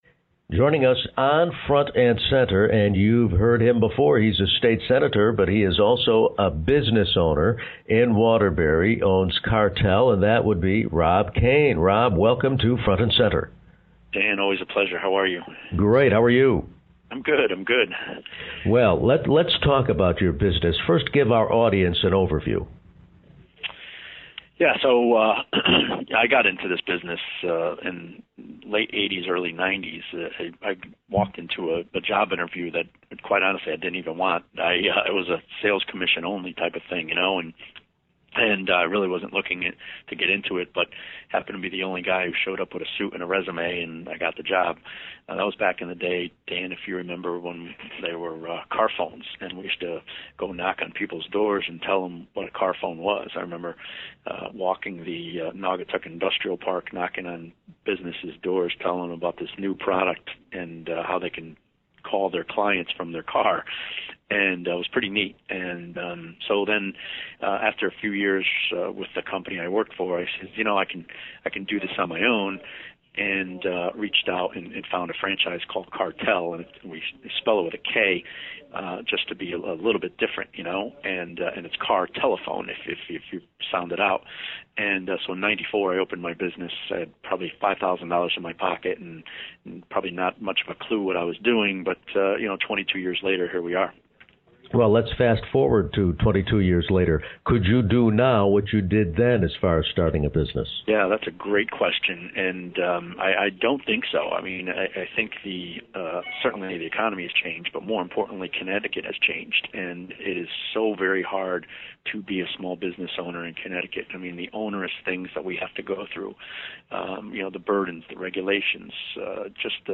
But Sen. Kane's full time job is owner of Kartele, a business which started out installing car phones. Kane talks about the history of the business and more